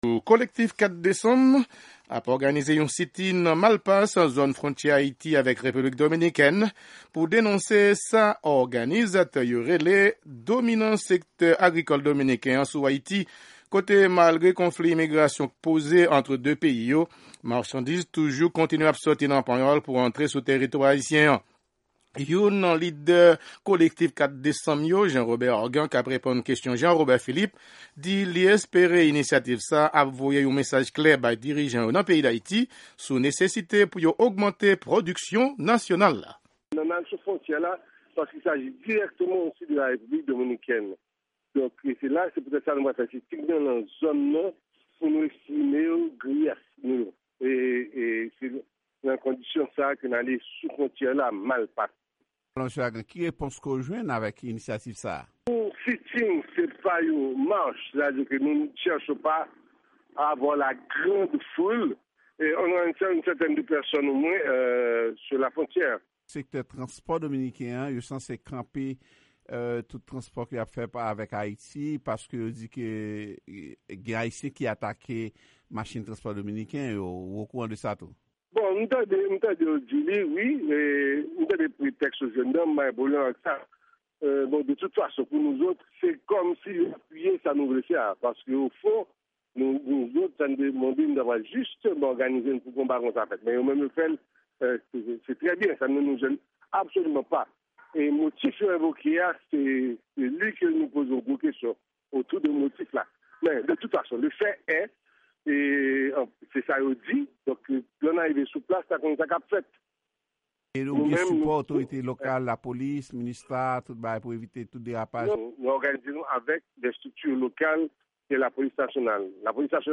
• Ekip Sèvis Kreyòl VOA